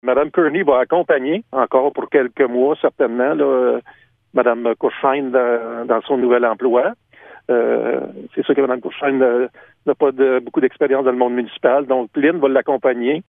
Le maire de Sainte-Thérèse-de-la-Gatineau, Roch Carpentier, commente :